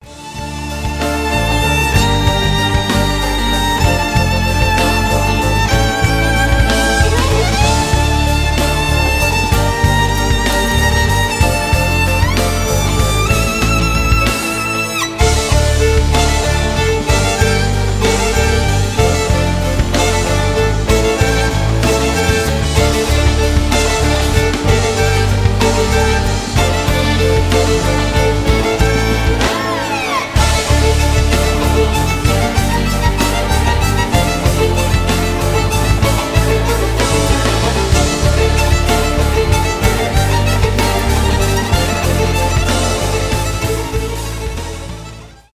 классические , инструментальные , вальс , скрипка
оркестр